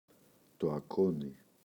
ακόνι, το [aꞋkoɲi]